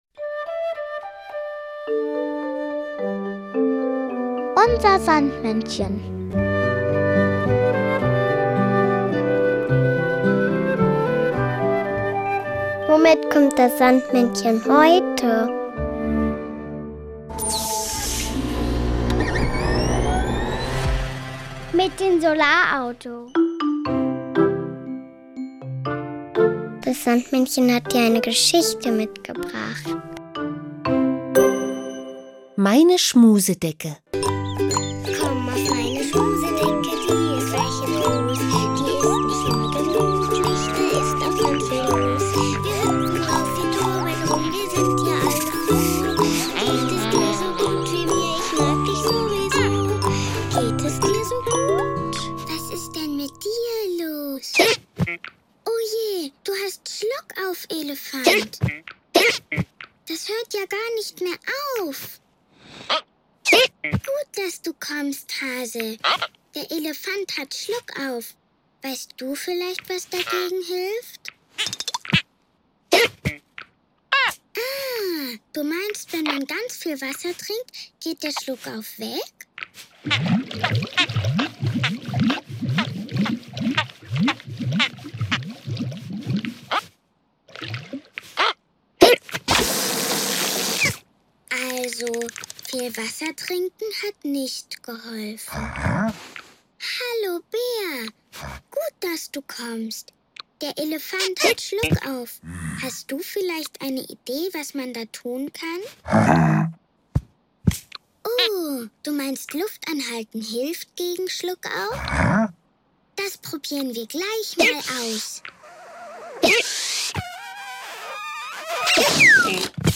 UNSER SANDMÄNNCHEN bringt die Geschichten der beliebten Sandmannserien zum Hören mit.